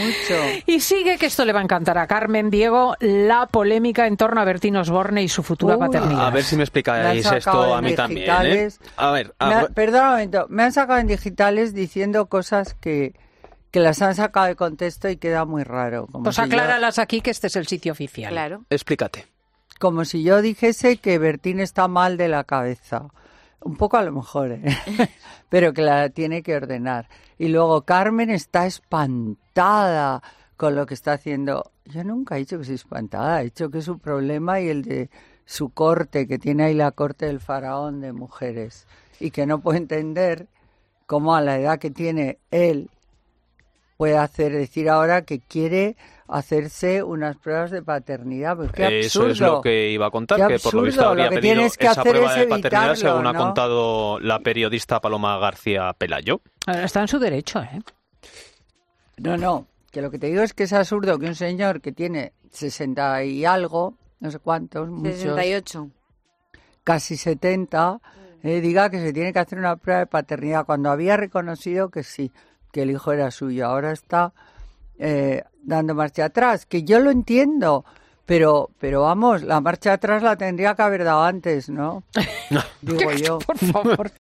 La socialité ha querido responder a las últimas intervenciones del artista y ha destadado las carcajadas del equipo de 'Fin de Semana'
"Es un señor que tiene casi 70 años no puede decir que tiene que hacerse una prueba de paternidad cuando había reconocido que el hijo era suyo. Ahora da marcha atrás, lo entiendo, pero la tenía que haber dado antes" decía, desatando las risas de todo el equipo de Fin de Semana.